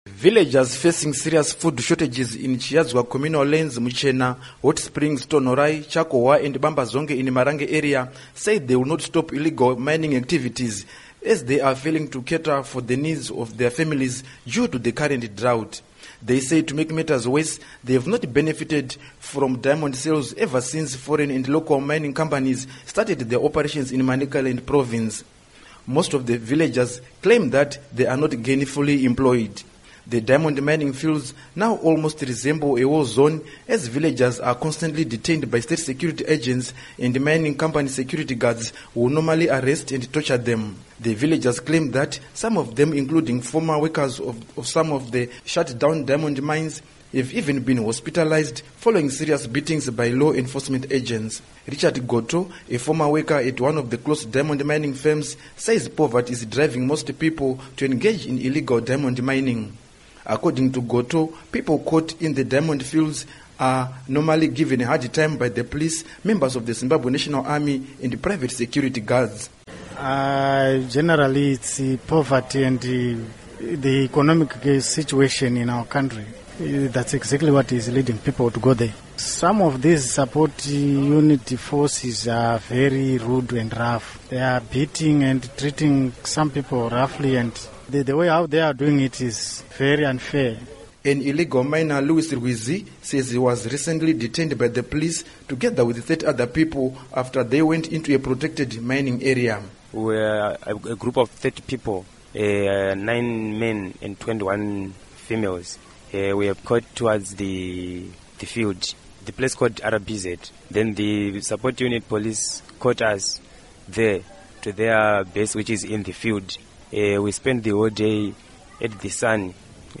Report on Diamond Illegal Activities